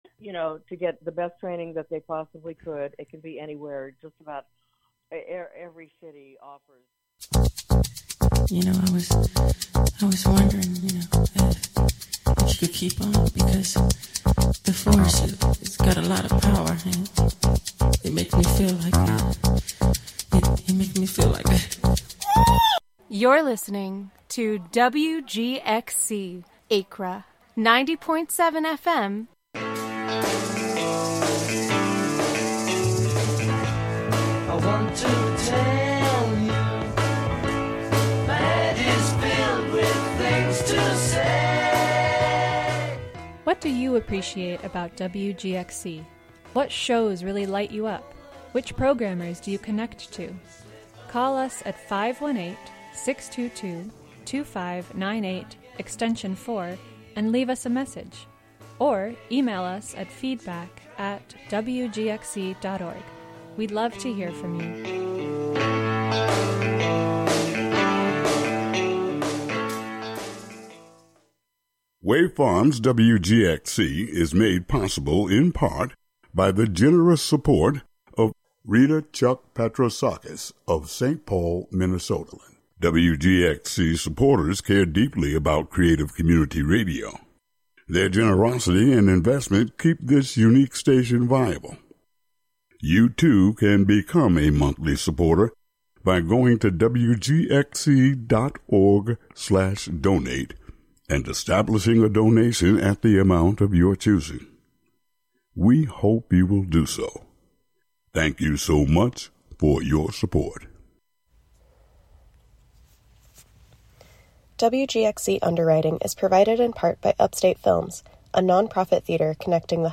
Tune into the “Wednesday Afternoon Show” for national news, interviews with community leaders and personalities, reports on cultural issues, a rundown of public meetings and local and regional events, weather updates, and more about and for the community, made by volunteers in the community.